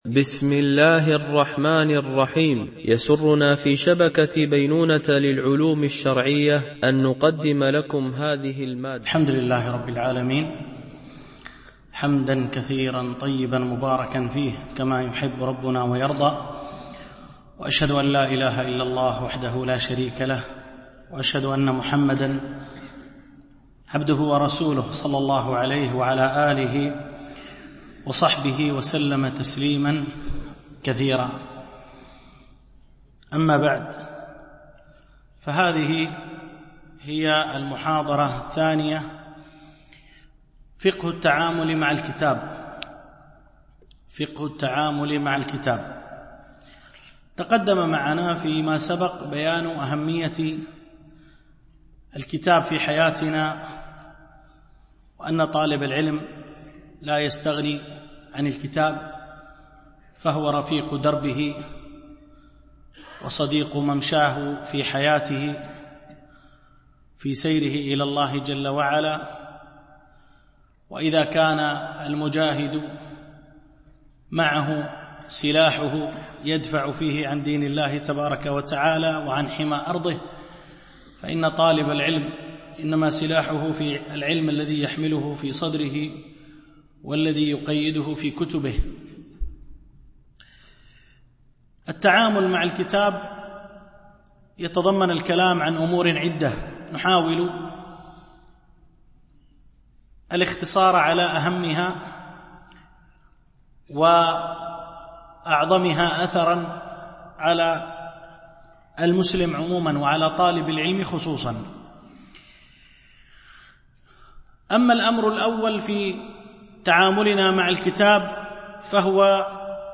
دورة علمية شرعية